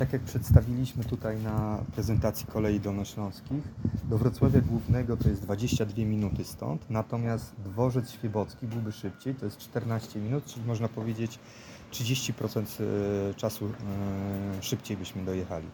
Na zorganizowanym dziś na dworcu kolejowym w Smolcu briefingu, burmistrz Miasta i Gminy Kąty Wrocławskie – Julian Żygadło mówił o rozwoju współpracy pomiędzy gminą a Kolejami Dolnośląskimi.